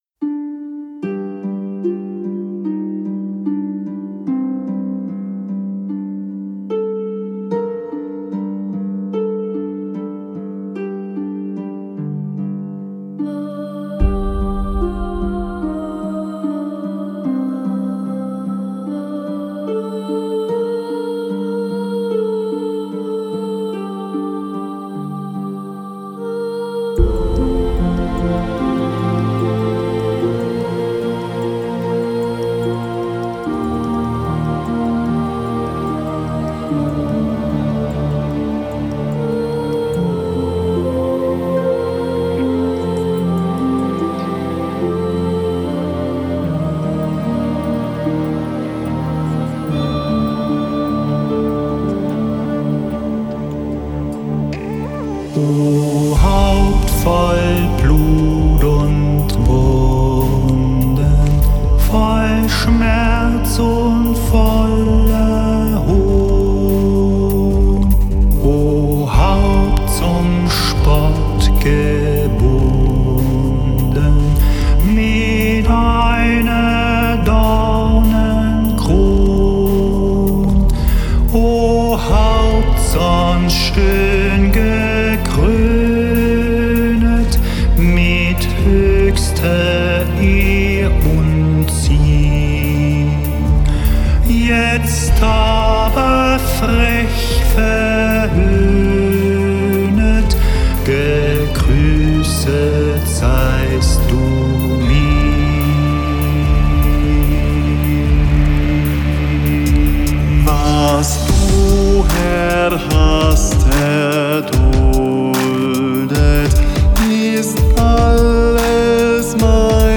Genre: Gregorian chant / Pop-Mystic / Choral
/ Neo-Classical / New Age